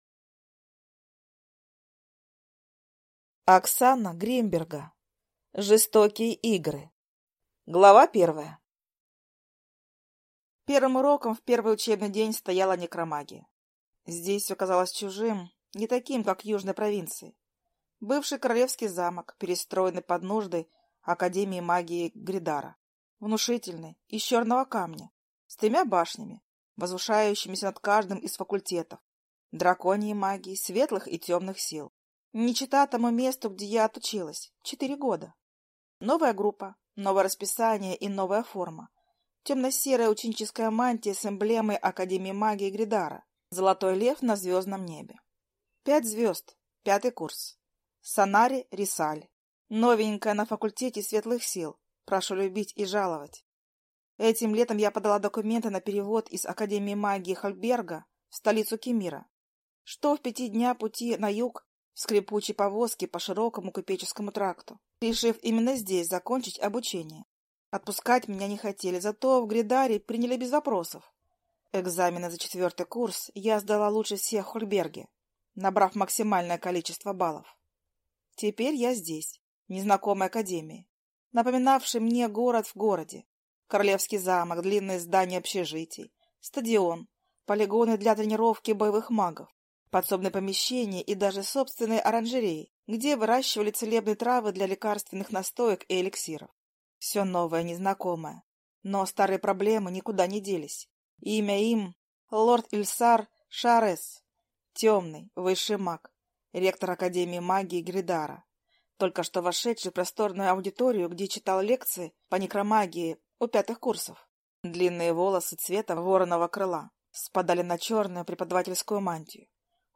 Аудиокнига Жестокие Игры. Магическая Академия | Библиотека аудиокниг